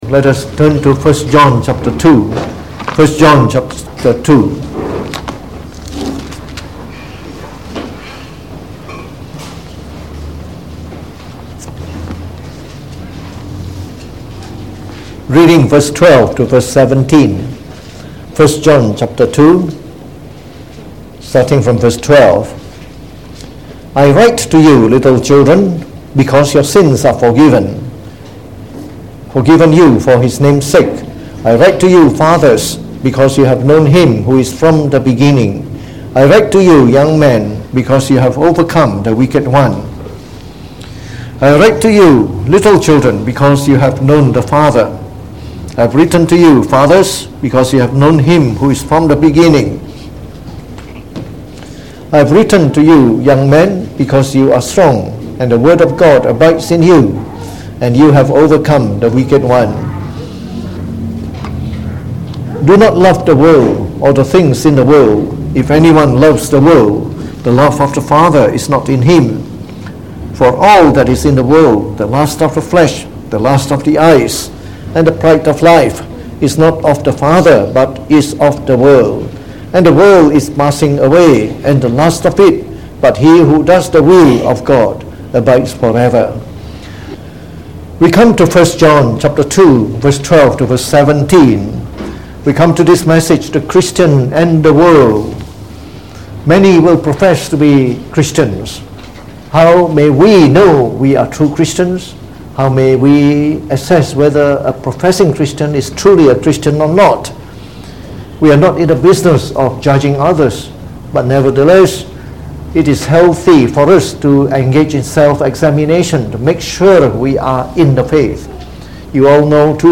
From our series on the Epistle of 1 John delivered in the Evening Service